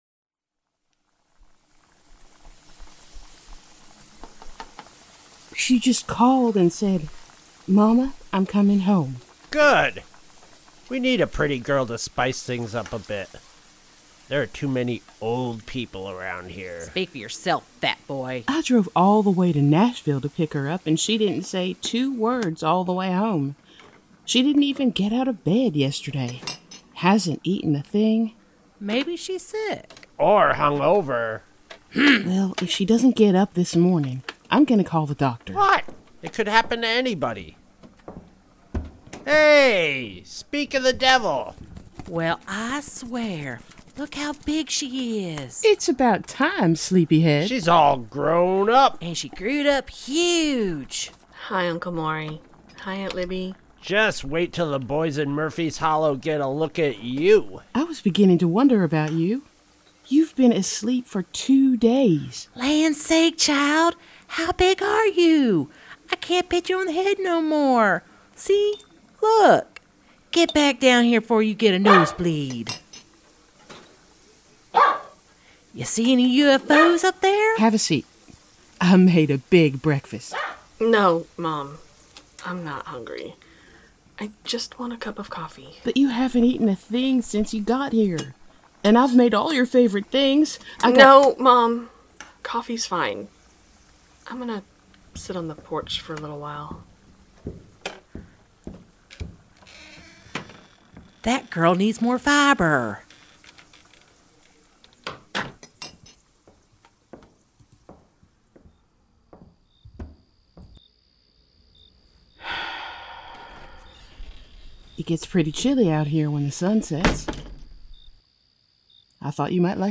Strangers In Paradise – The Audio Drama – Book 7 – Episode 12 – Two True Freaks
The Ocadecagonagon Theater Group